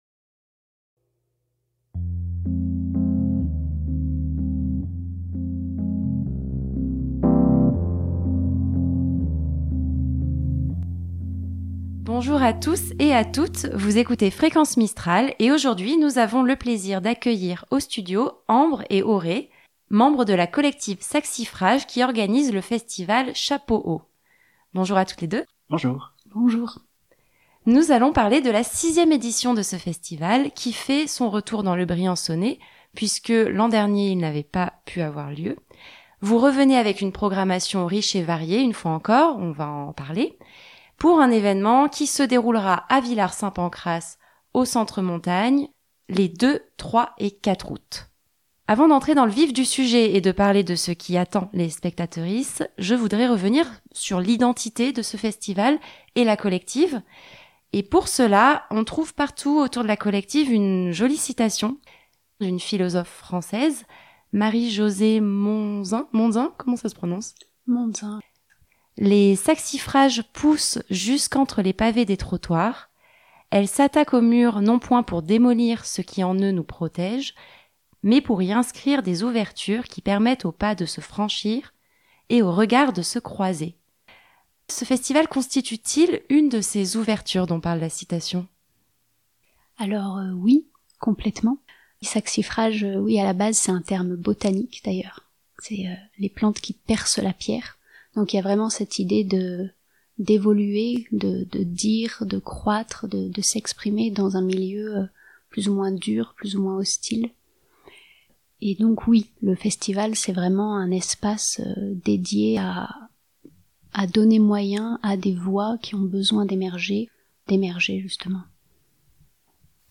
Nous avons accueillis au studio de Briançon